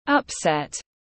Upset /ʌpˈset/